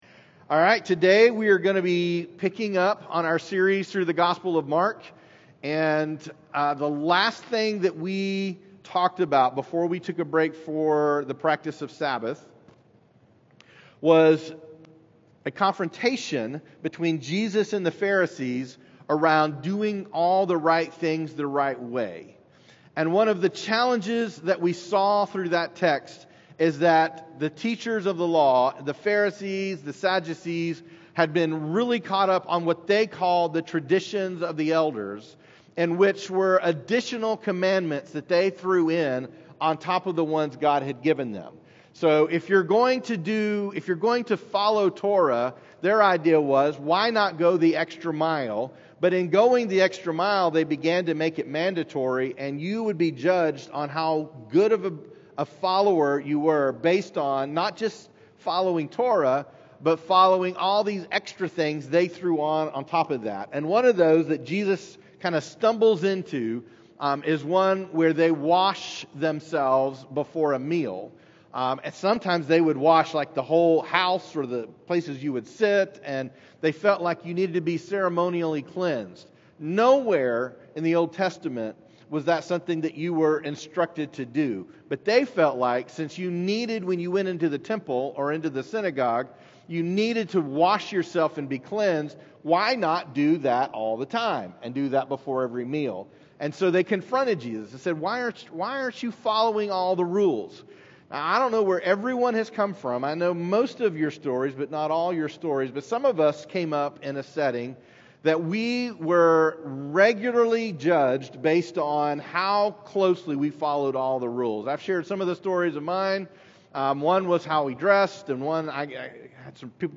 Weekly teaching of Journey Church: A faith community in Chattanooga devoted to making Jesus famous and healing the brokenhearted.